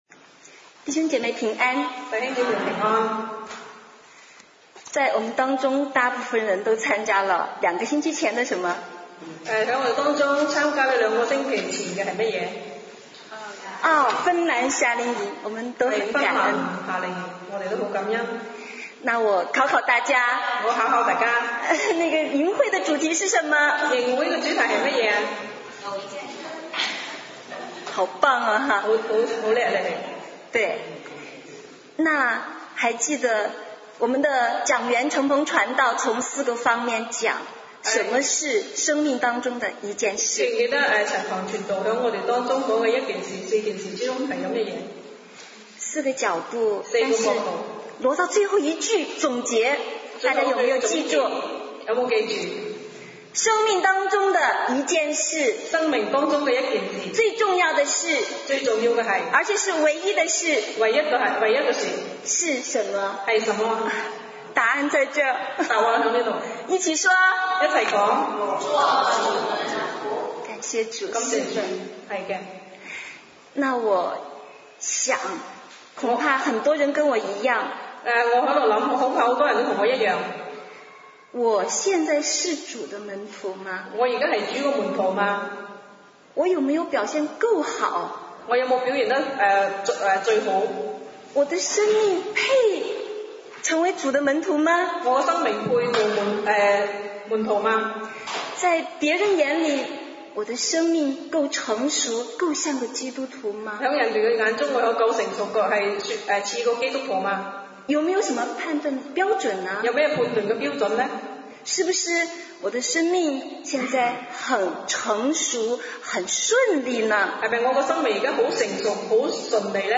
主日讲道音频
(国语翻粤语)